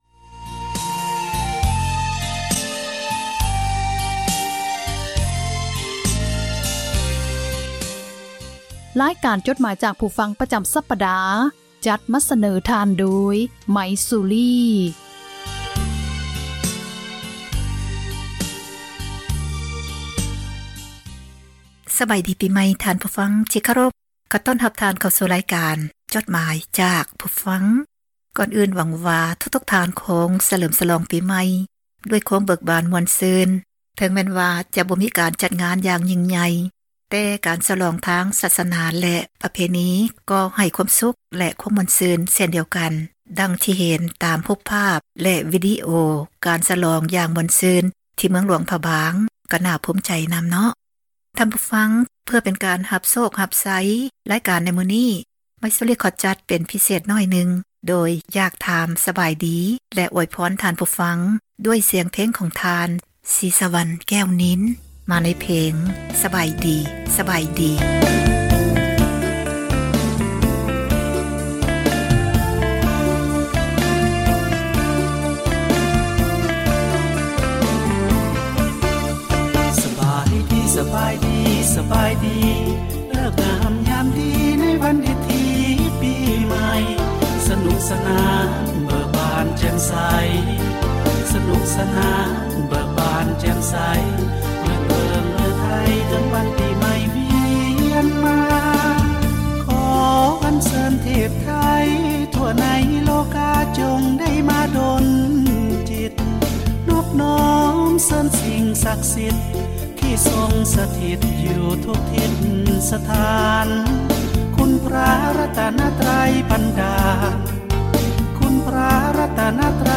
ອ່ານຈົດໝາຍ, ຄວາມຄຶດຄວາມເຫັນ ຂອງທ່ານ ສູ່ກັນຟັງ